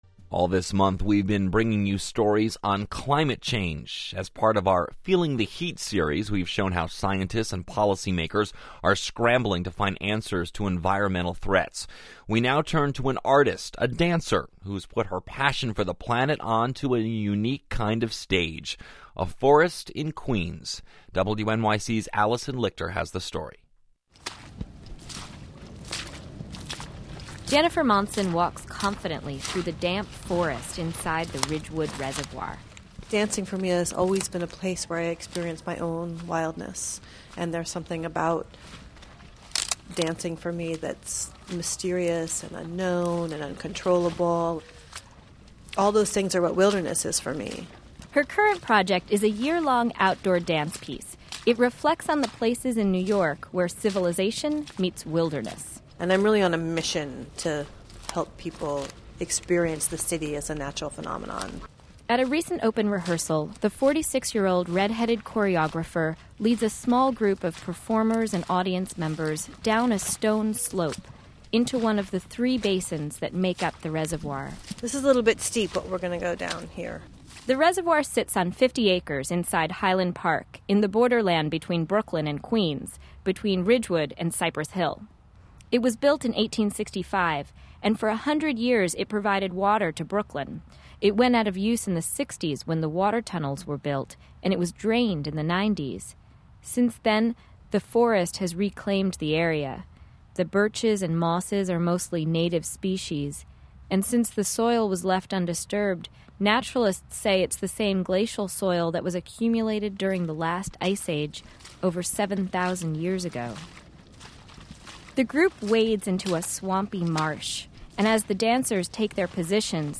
WNYC Interview – April 26, 2007 (mp3)